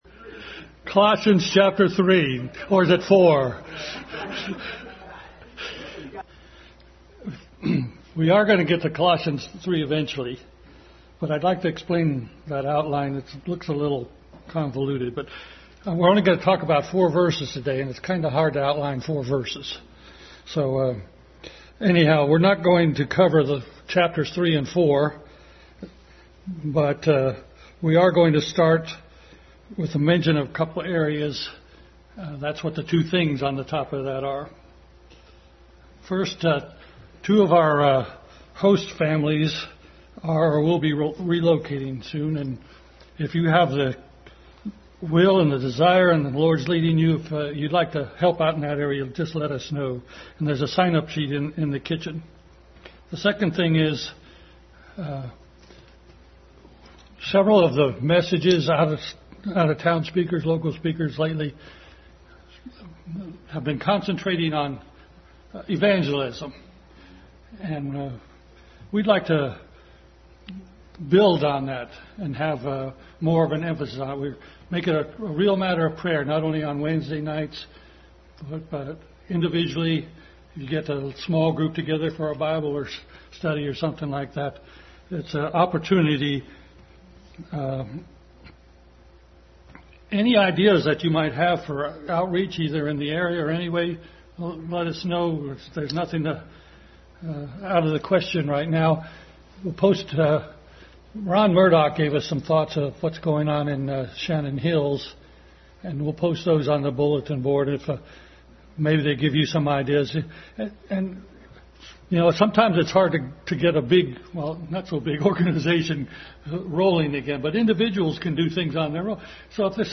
Adult Sunday School continued study in Colossians.